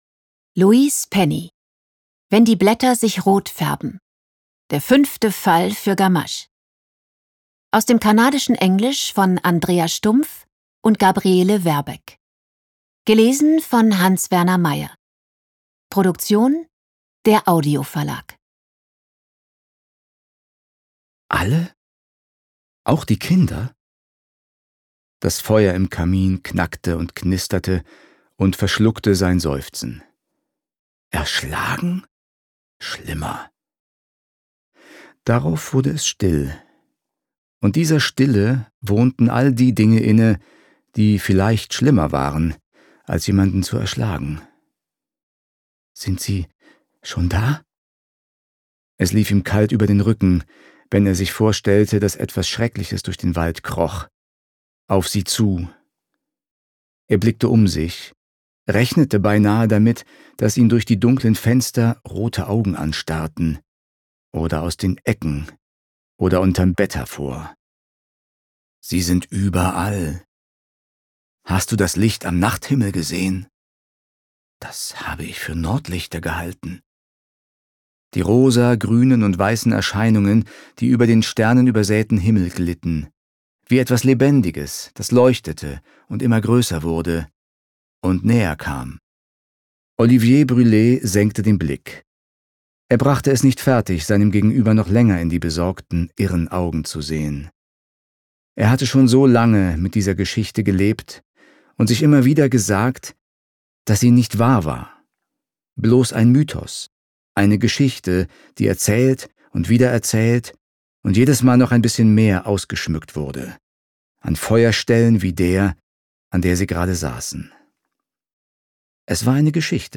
Ungekürzte Lesung mit Hans-Werner Meyer (2 mp3-CDs)
Hans-Werner Meyer (Sprecher)